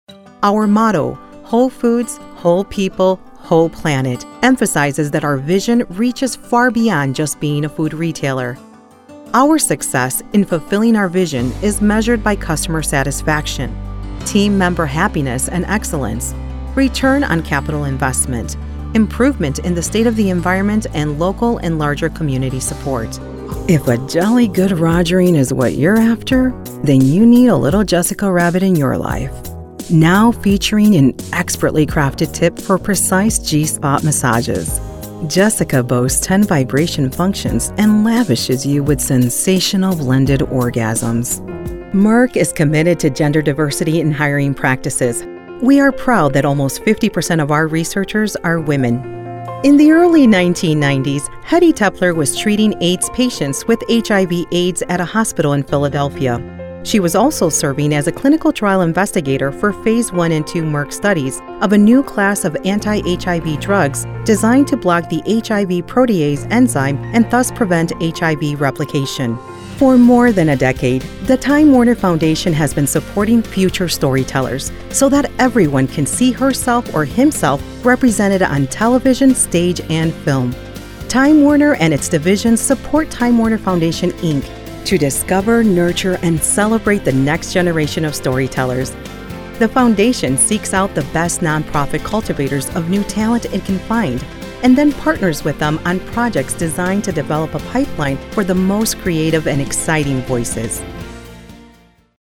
Corporate Narration